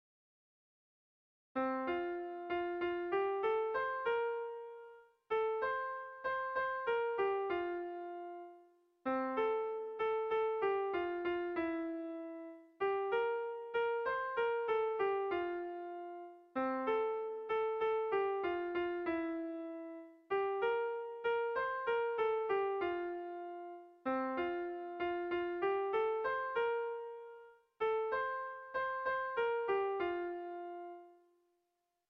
Dantzakoa
Bertsolari
Doinu herrikoia
Zortziko txikia (hg) / Lau puntuko txikia (ip)
ABBA